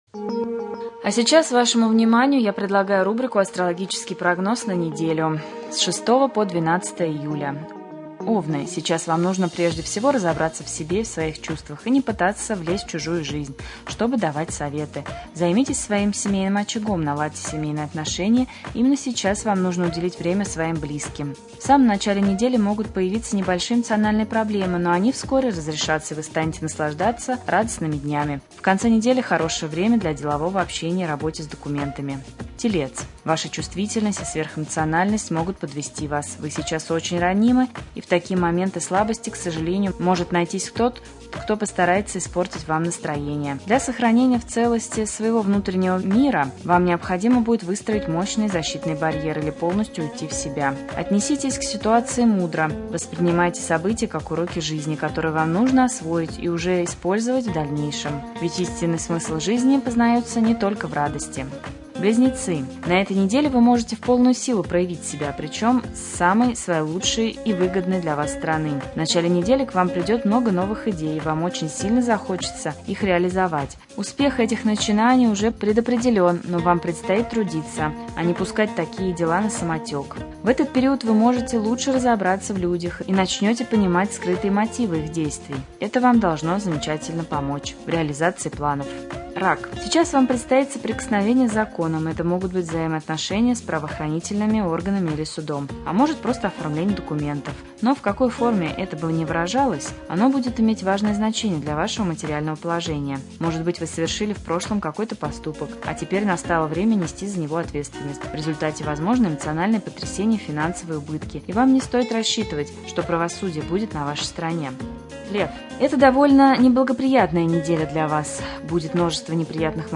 1.Рубрика «Депутат в эфире». В прямом эфире депутат районного Совета депутатов Пронин Виктор Алексеевич.